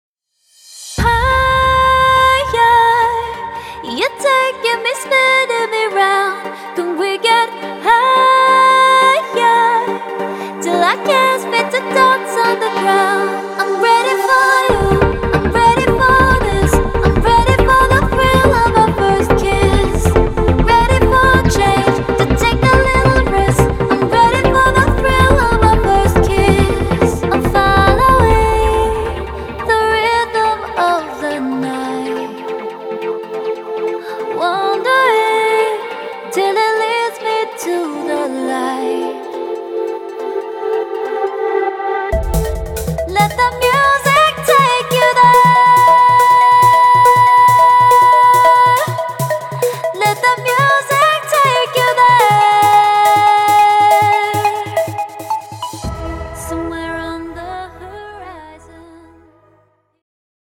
using high-end mics and hardware